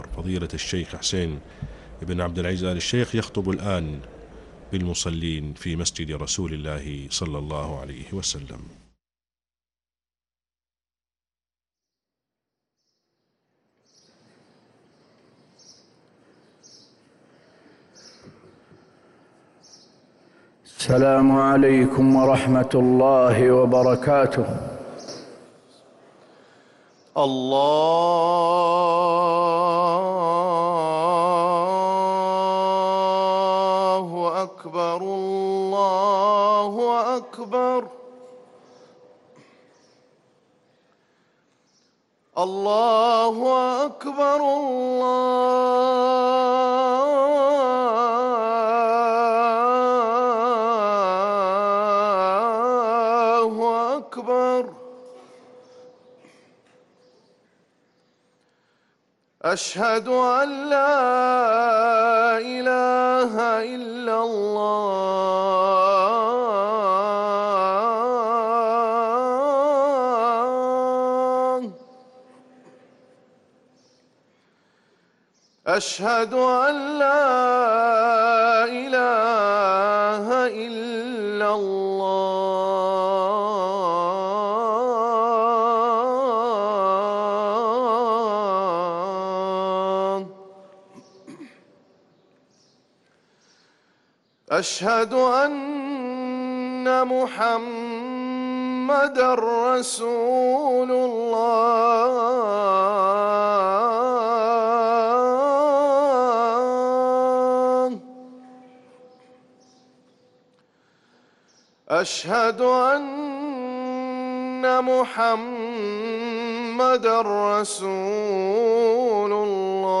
أذان الجمعة الثاني